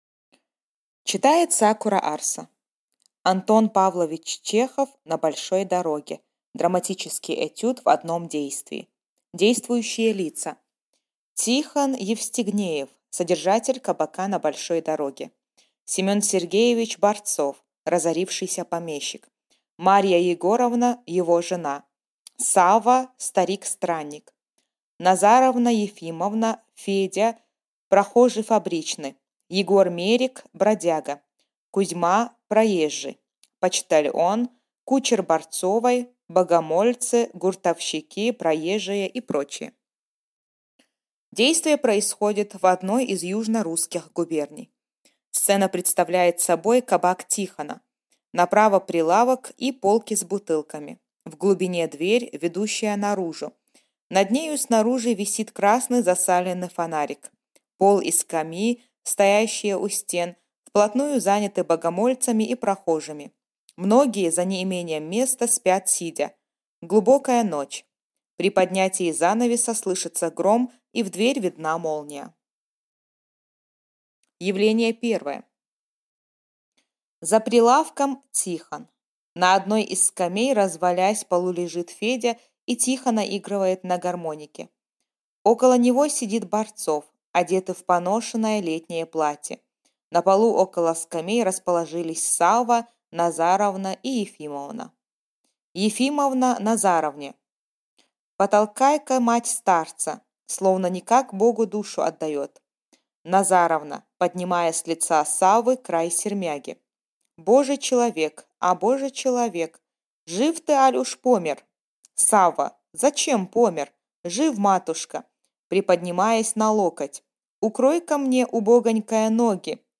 Аудиокнига На большой дороге | Библиотека аудиокниг